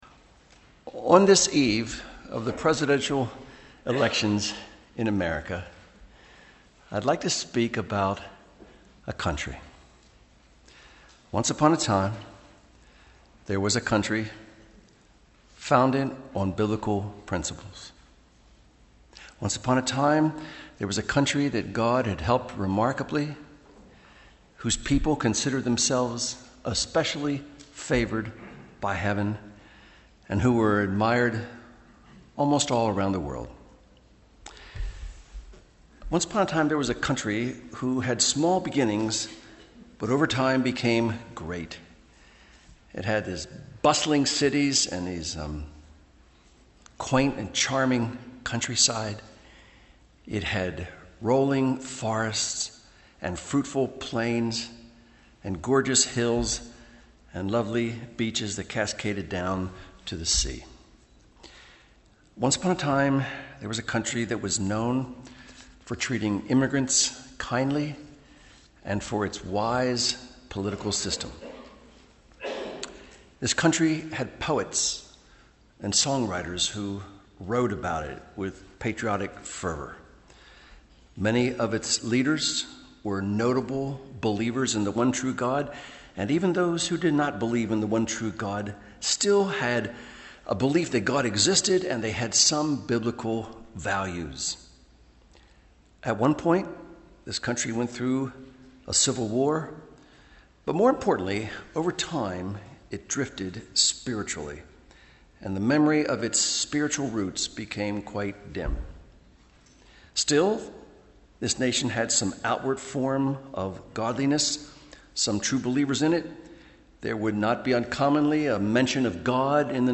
Sermons on 2 Kings 6:8-23 — Audio Sermons — Brick Lane Community Church